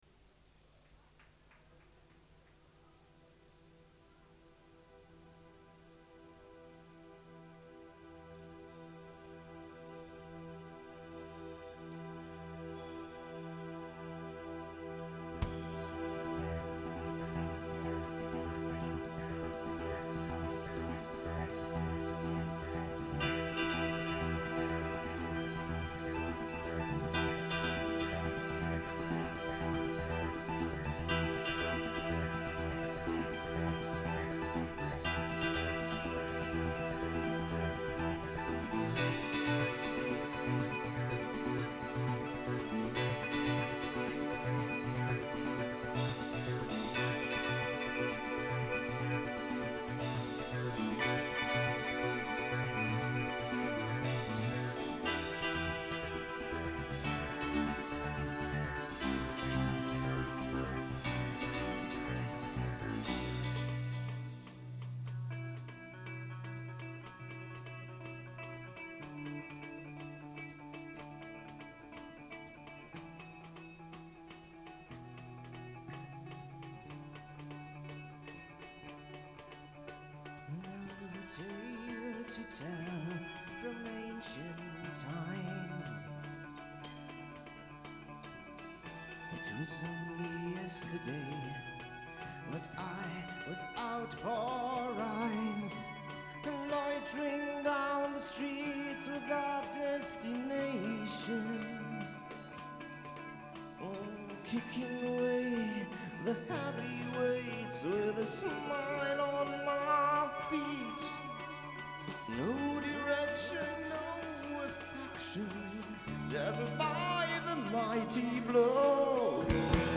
vocals
keyboards
guitar
drums
bass guitar